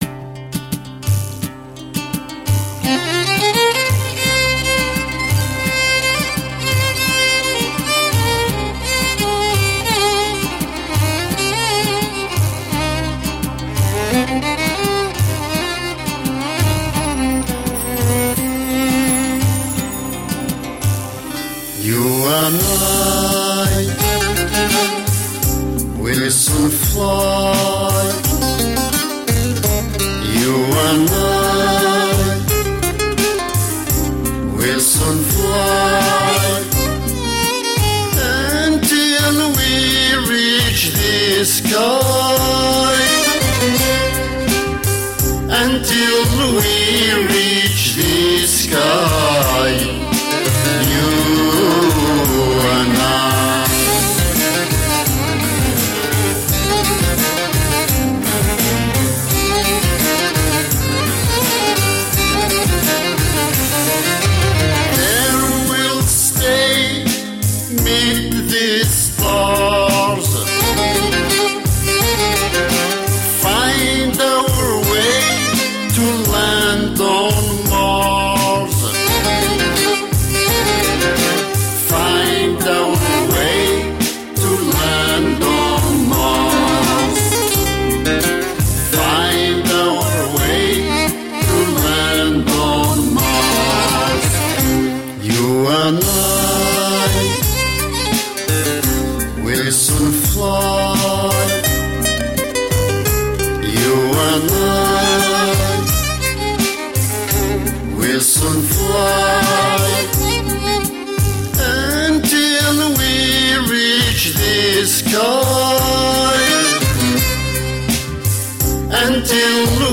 Music of the mountains of kabylia.
Tagged as: World, Folk, Arabic influenced, World Influenced